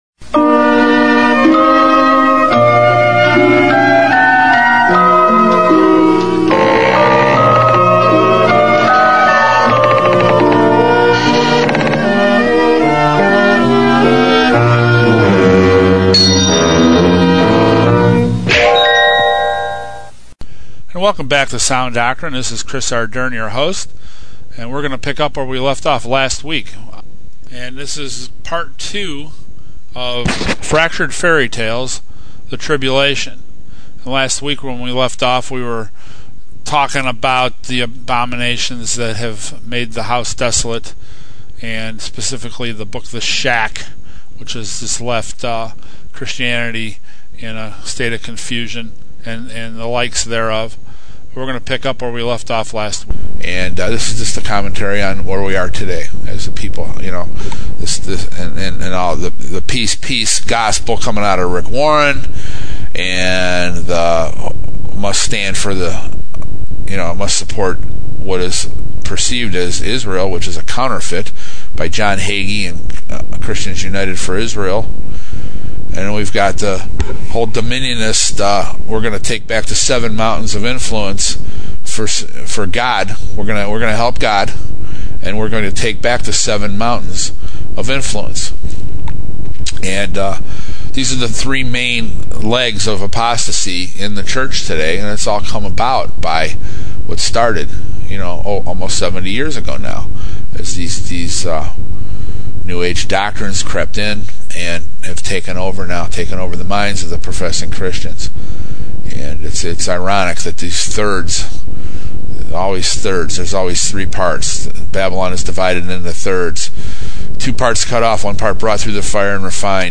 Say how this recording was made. Our shows are posted on the GEO network and require editing to fit the half hour slots.